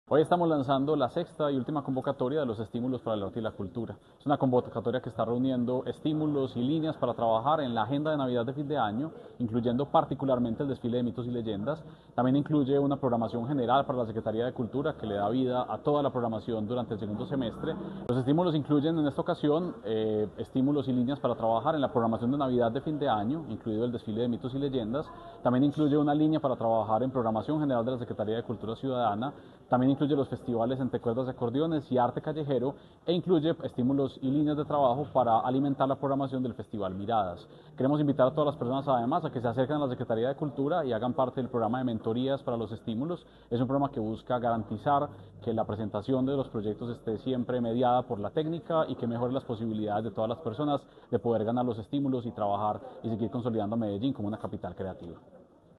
Declaraciones del secretario de Cultura Ciudadana, Santiago Silva Jaramillo.
Declaraciones-del-secretario-de-Cultura-Ciudadana-Santiago-Silva-Jaramillo.-Convocatoria-Cultural.mp3